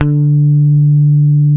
-JP PICK D 4.wav